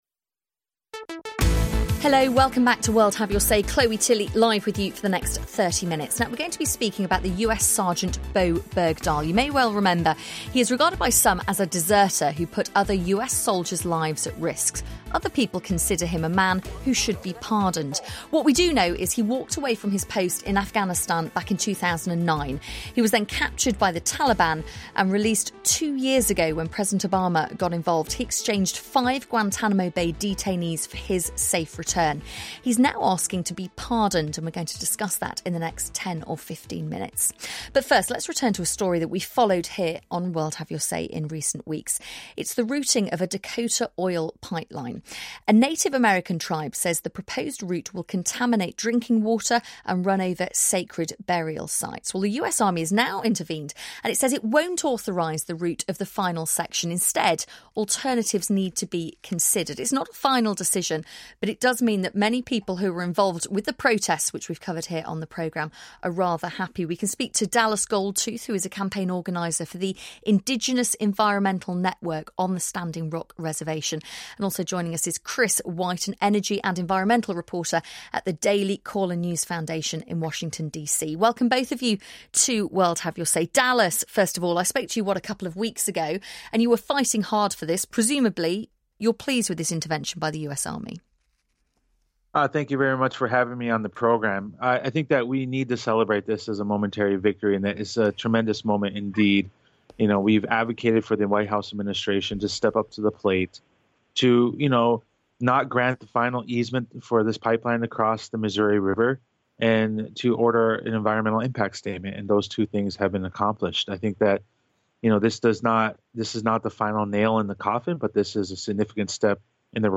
The round table discussion addresses the protesters, the pipeline, the state and whether the pipeline can be rerouted.